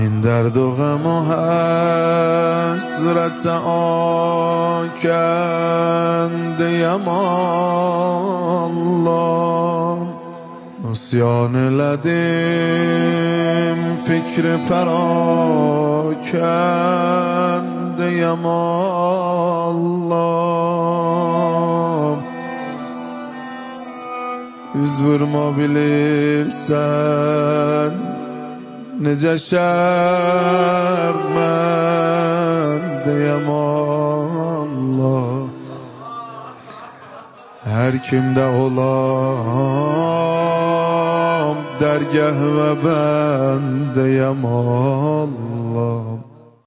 مناجات ماه مبارک رمضان مداحی آذری نوحه ترکی